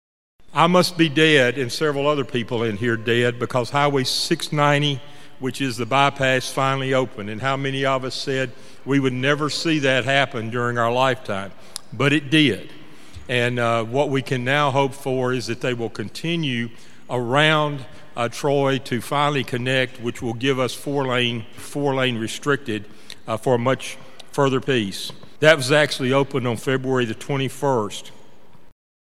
The Obion County Joint Economic Development Council and Chamber of Commerce held their annual banquet last week.
The banquet was held in front of a sold out crowd of 300 people at Discovery Park of America.